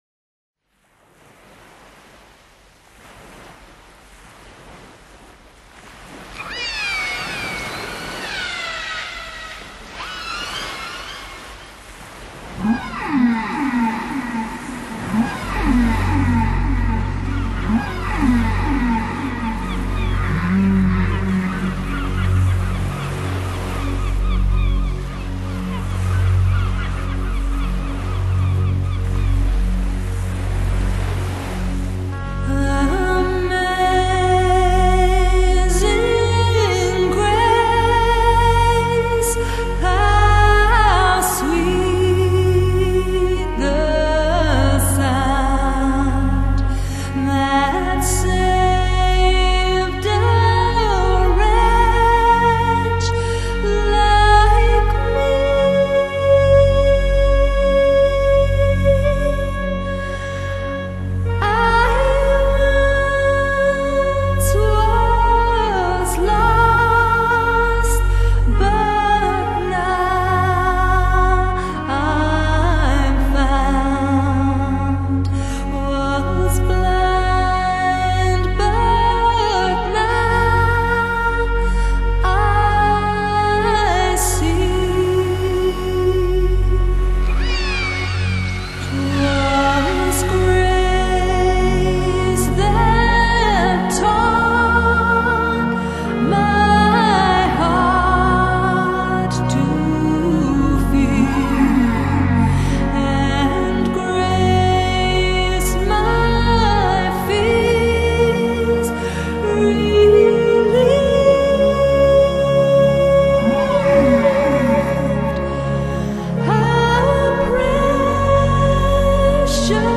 新世纪音乐